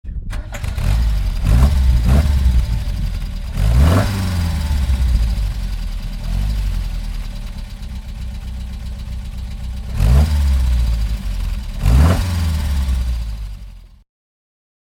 Ford Escort Mexico (1972) - Starten und Leerlauf
Ford_Escort_Mexico_1972.mp3